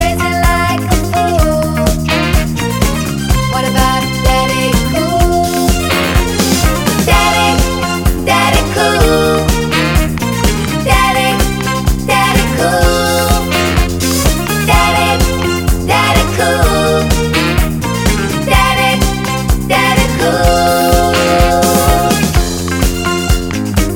Duet Disco 3:24 Buy £1.50